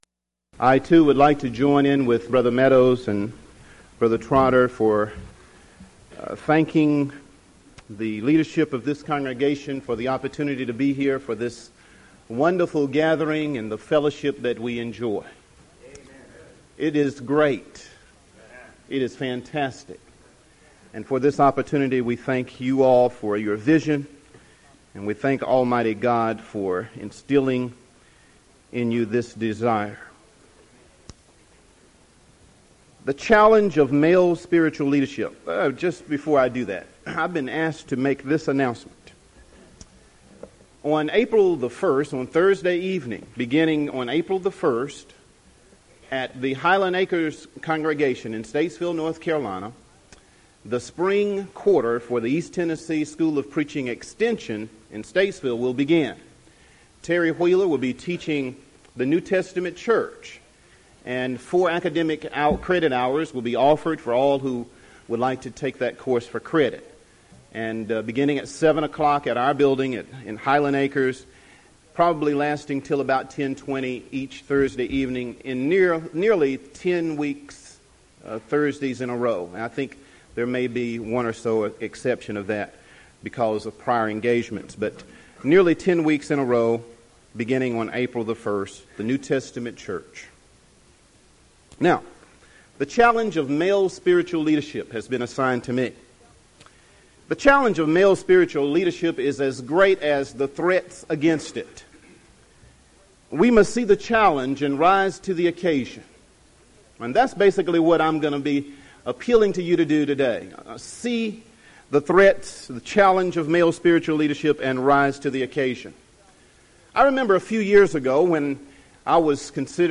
Event: 1999 Carolina Men's Fellowship
lecture